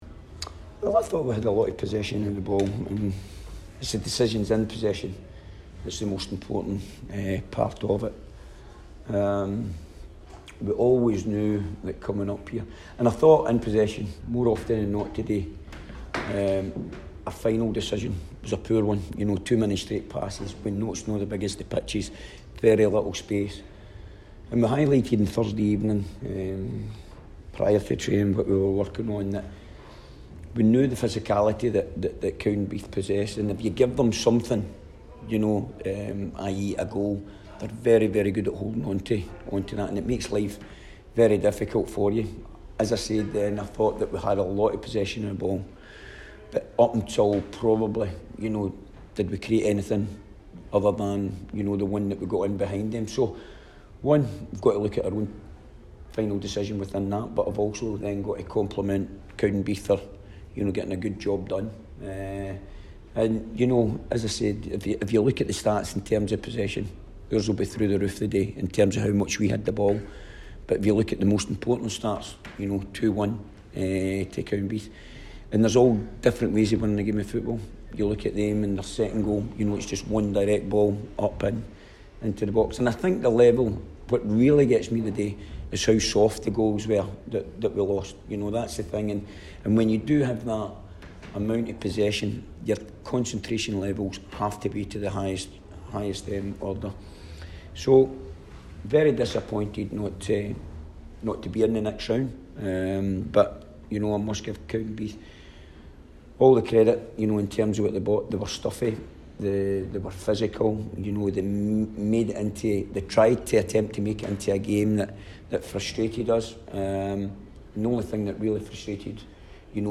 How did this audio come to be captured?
press conference after the William Hill Scottish Cup match.